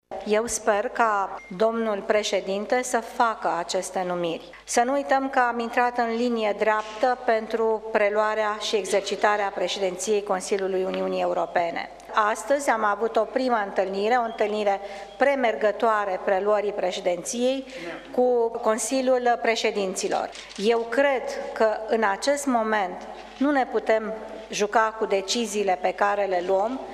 Viorica dăncilă a precizat ca își dorește ca președintele Klaus Iohannis să accepte cele două propuneri: